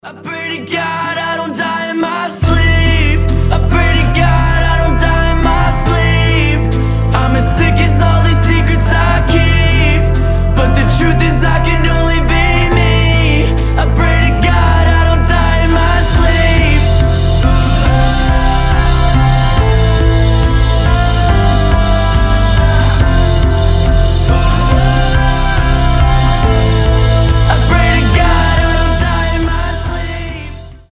• Качество: 320, Stereo
гитара
красивый мужской голос
alternative